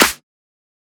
edm-clap-48.wav